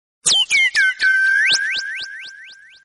Darmowe dzwonki - kategoria SMS
Dźwięk przypominający ufo.